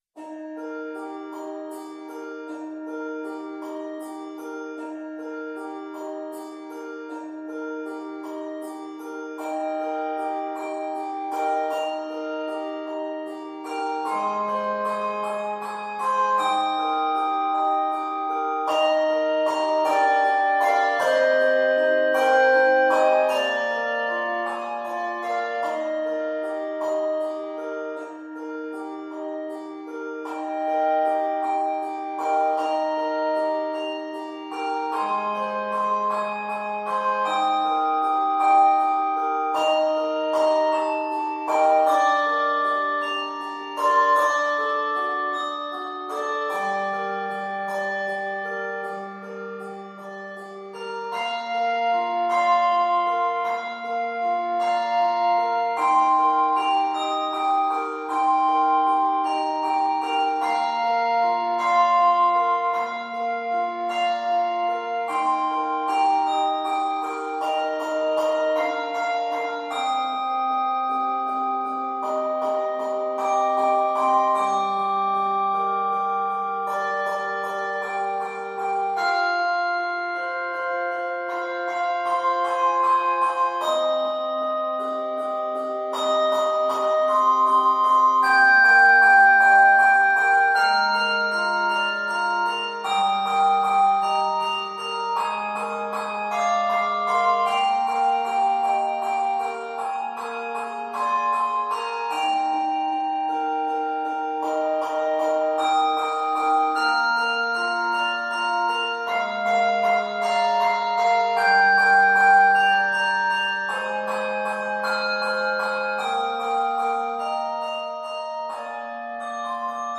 It is scored in Eb Major.
Octaves: 4